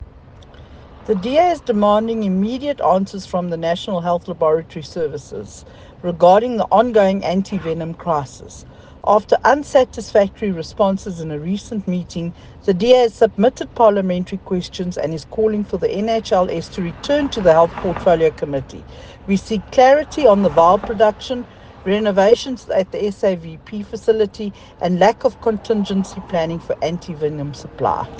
soundbite by Michele Clarke MP.